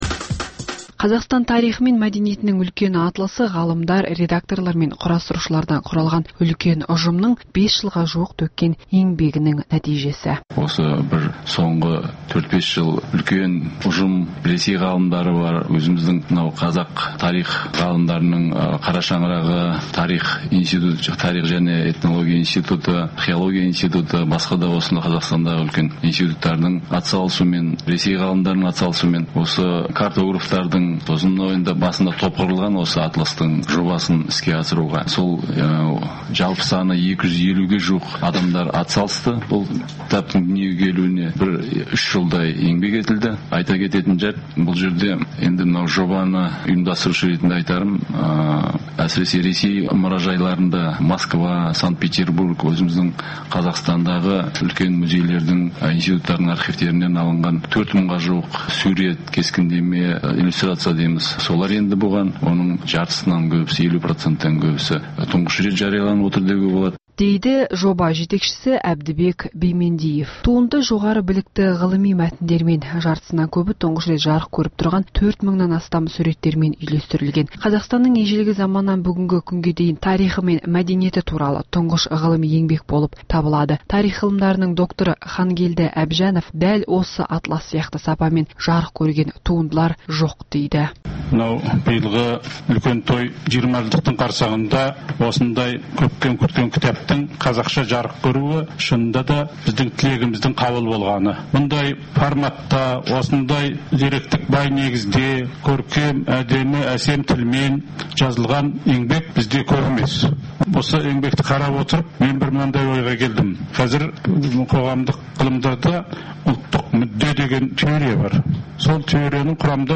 Апта тынысы – Апта бойына орын алған маңызды оқиға, жаңалықтарға құрылған апталық шолу хабары.